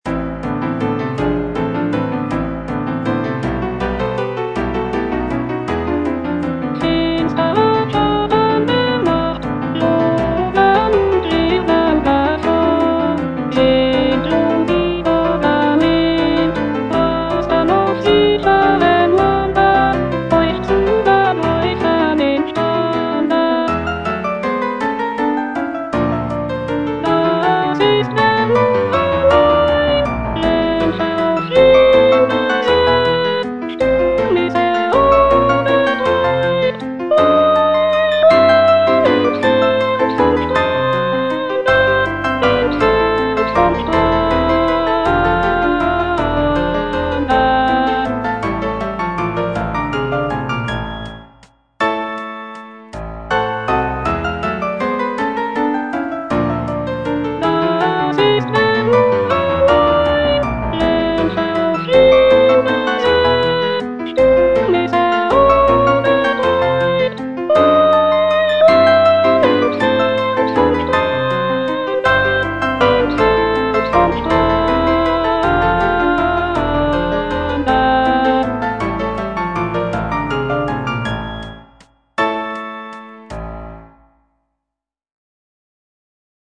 Choralplayer playing Choral pieces from
J. BRAHMS - CHORAL PIECES FROM "NEUE LIEBESLIEDER WALZER" OP.65 2 - Finstere Schatten der Nacht - Alto (Voice with metronome) Ads stop: auto-stop